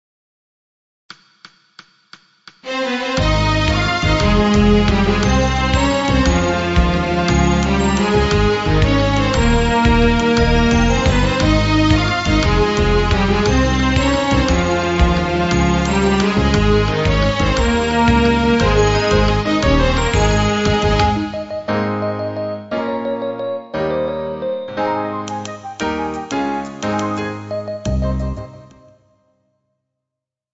• Backing Track: Midi File